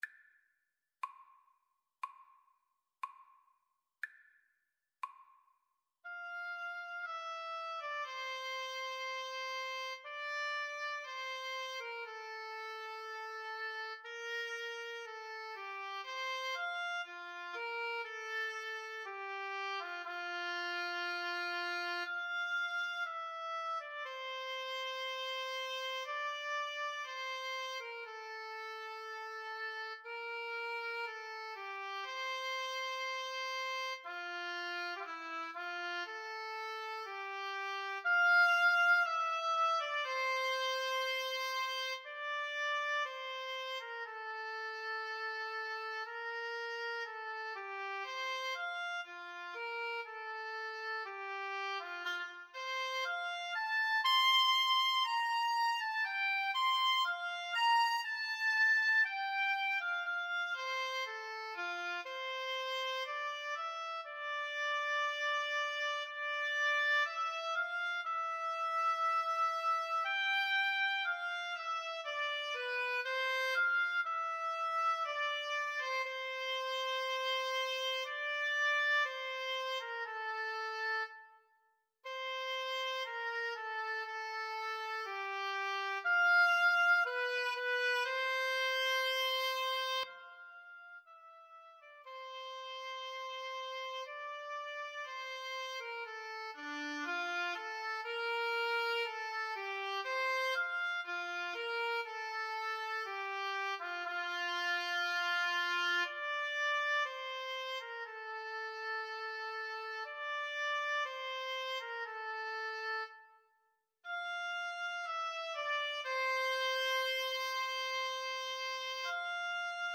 Free Sheet music for Oboe-Cello Duet
4/4 (View more 4/4 Music)
F major (Sounding Pitch) (View more F major Music for Oboe-Cello Duet )
Larghetto =60
Classical (View more Classical Oboe-Cello Duet Music)